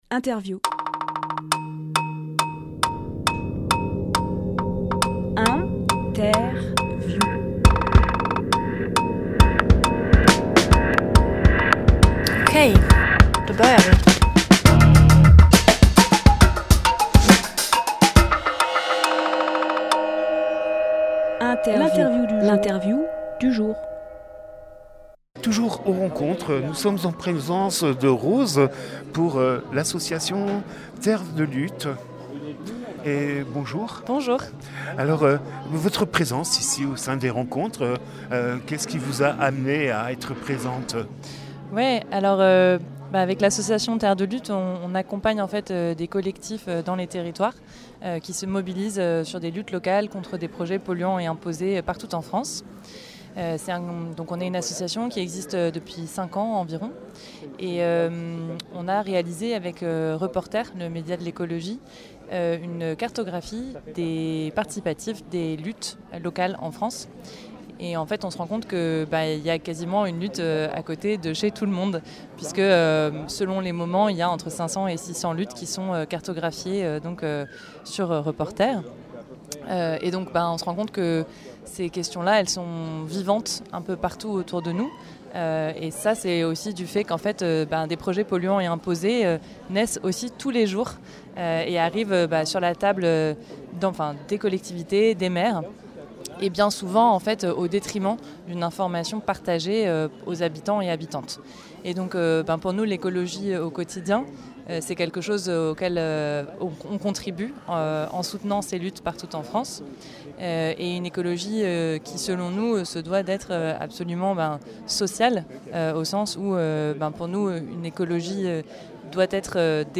Emission - Interview Rencontres Die-Biovallée-Drôme : Terres de luttes Publié le 1 février 2025 Partager sur…
lieu : Studio Rdwa